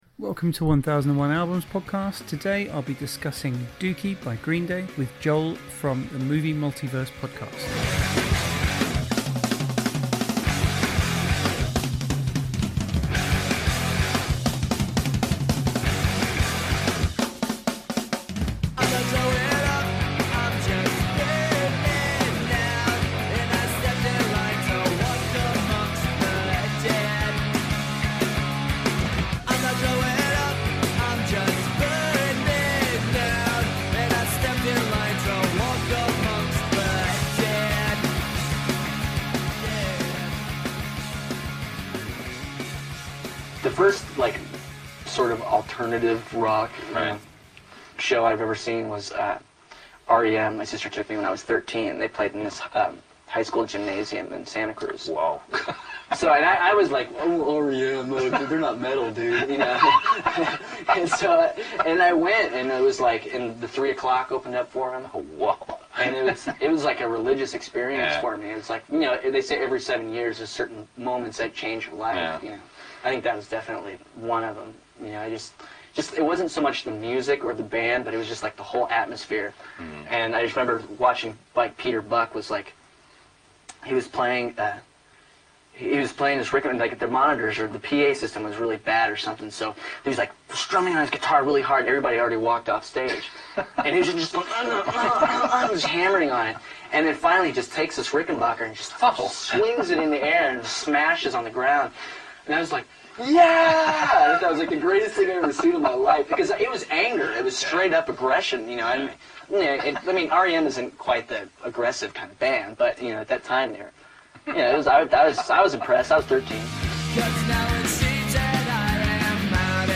Billie Joe Interview - MTV 1994